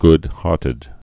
(gdhärtĭd)